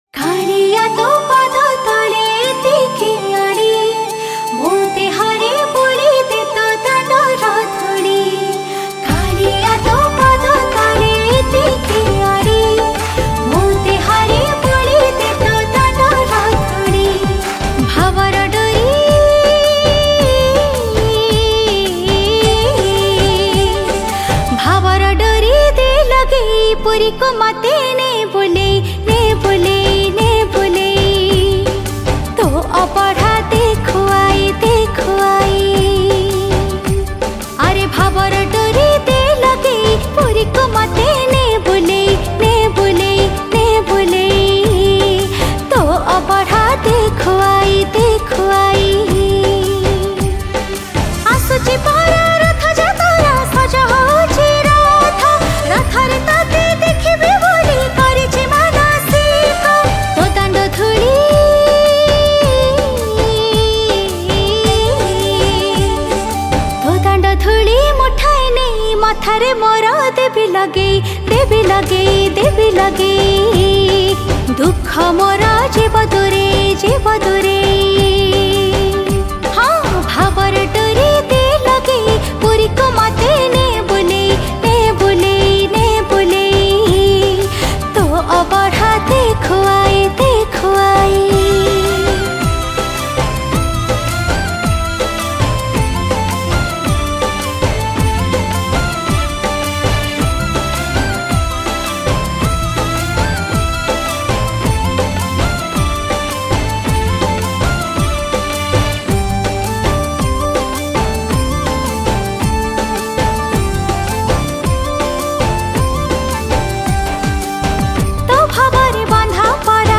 Odia Bhajana